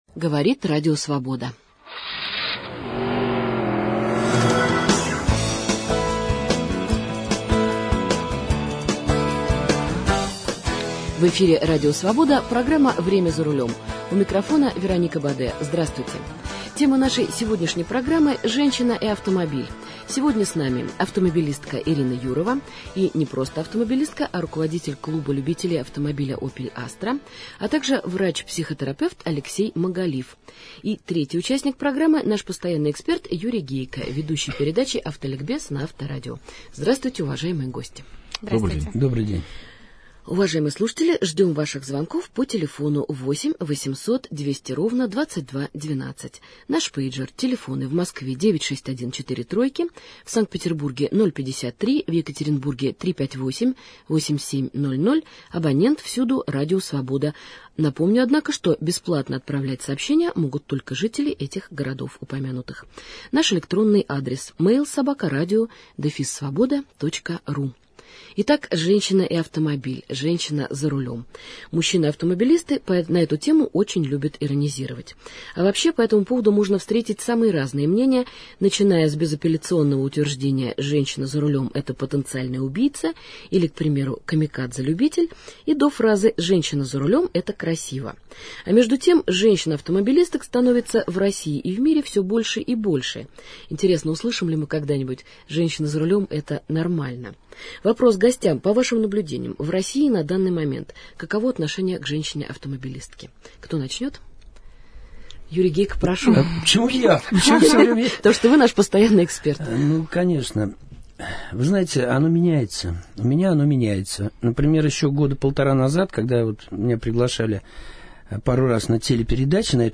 Аудио интервью разное